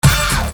Hit 004.wav